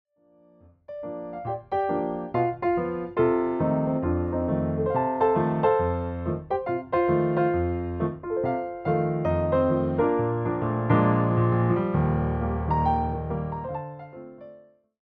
The performance favors clean voicing and balanced dynamics